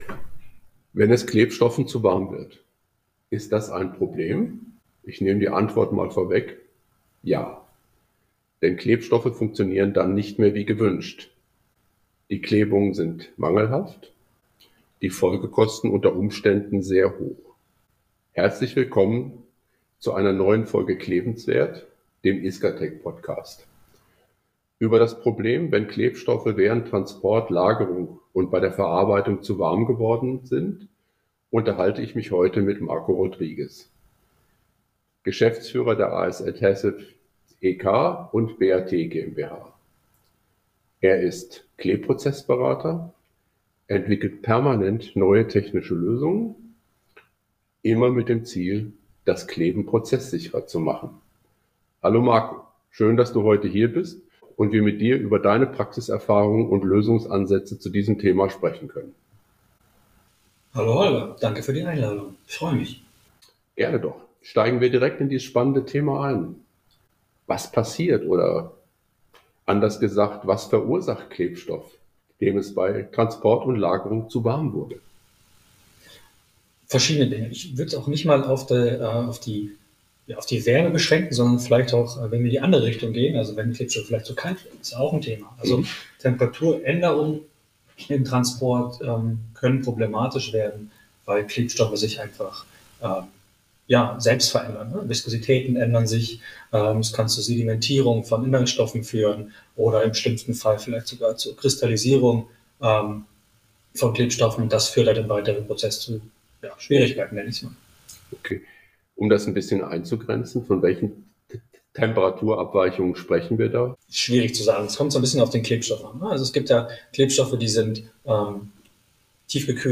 Temperatur und Luftfeuchtigkeit bei Transport und Verarbeitung haben einen erheblichen Einfluss darauf, ob ein Klebstoff später die gewünschte Performance bringt. Im Gespräch